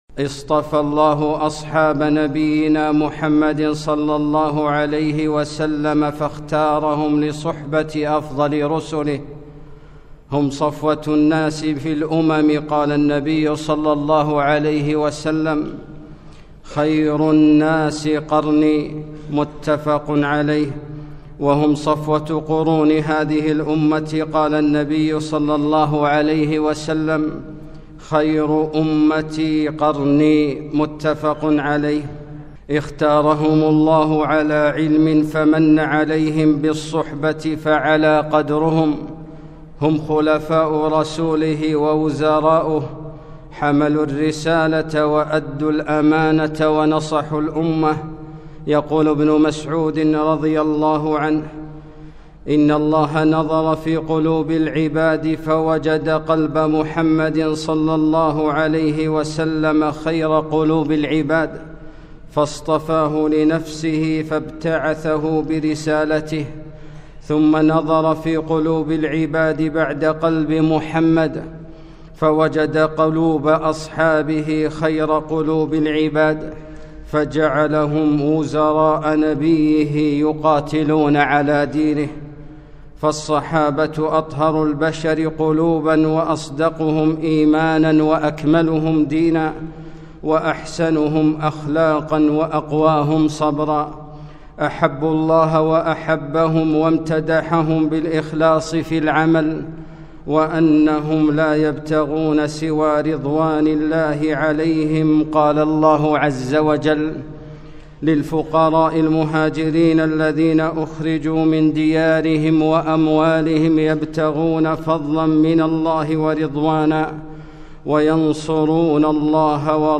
خطبة - السابقون الأولون